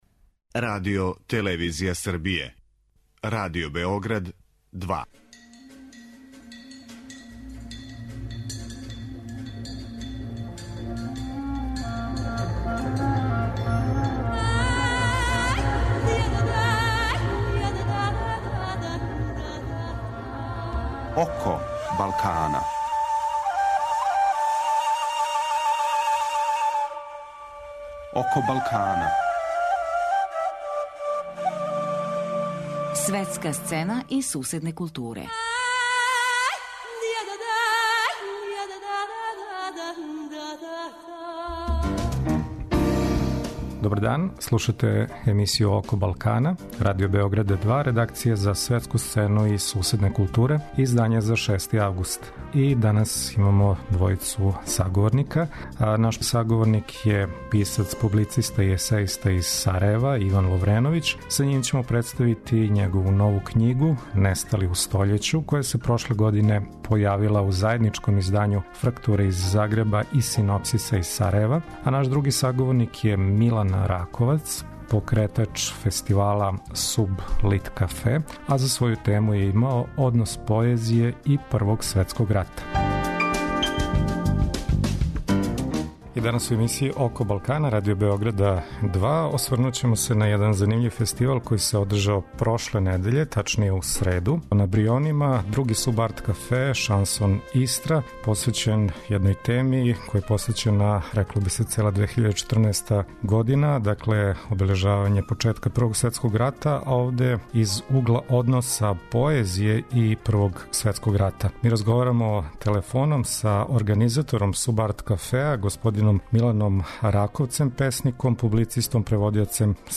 Гост емисије је Иван Ловреновић, романописац, есејиста и публициста из Сарајева.